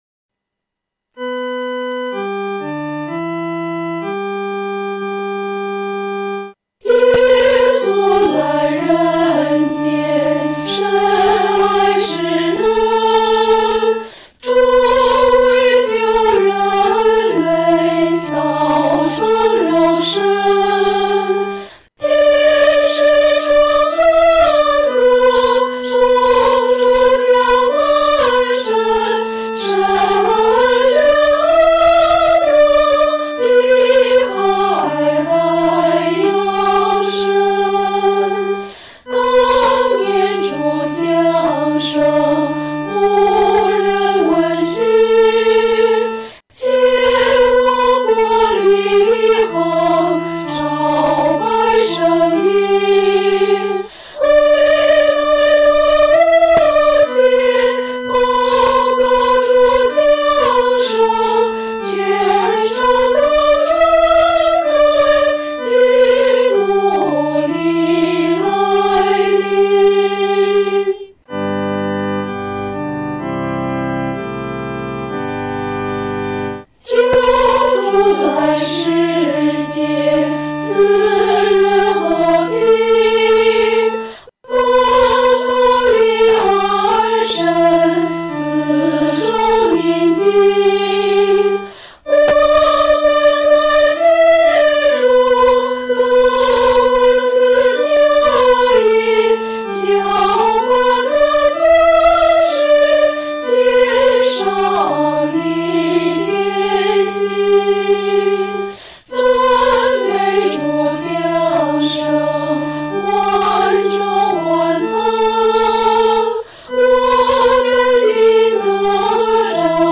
合唱
女高
本首圣诗由网上圣诗班（环球）录制
这首诗歌曲调是按歌词的内容、意境逐渐铺开进行的。前两句平稳幽静，悠然从容。第3句引吭高歌，似与天使一同唱和赞主降生。